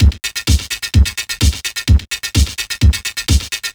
VMH1 Minimal Beats 01.wav